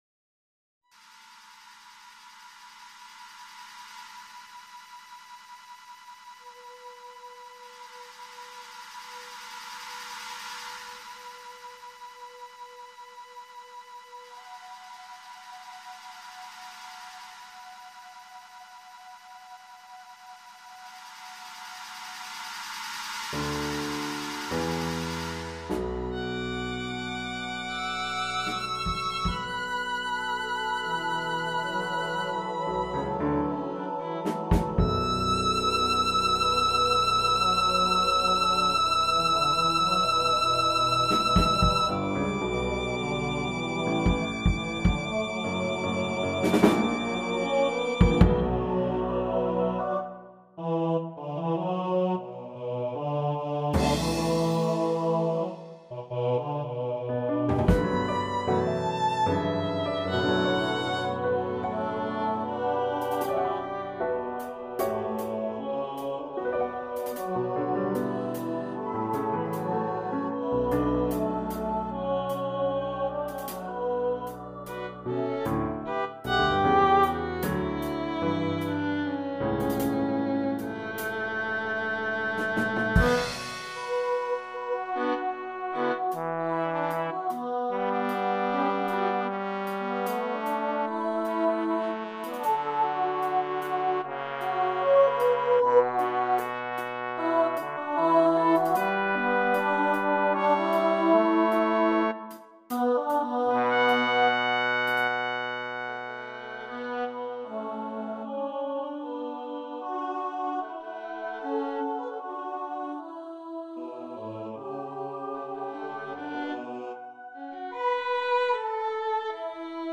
Moonscape Fallen (SATB, solo voice, drums, keyboard, and violin) – 2010
Listen to Moonscape Fallen (pre-premiere cg demo)